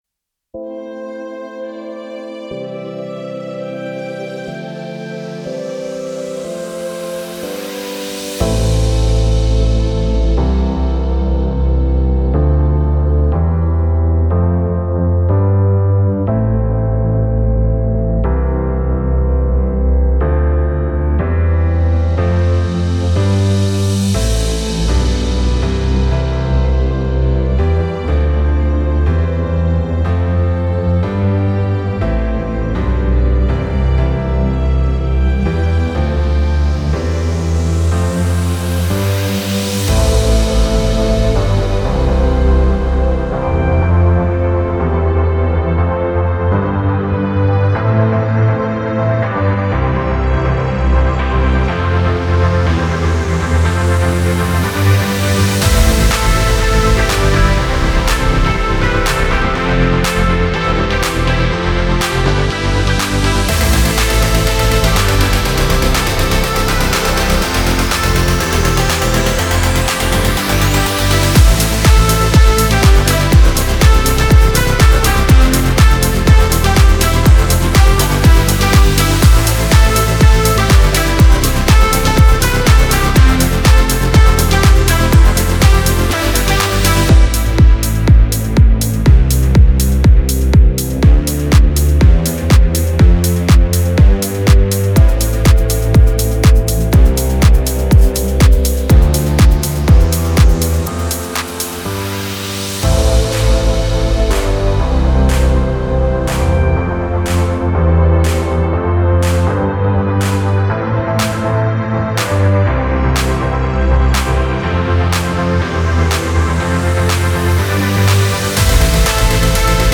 Download Instrumental Version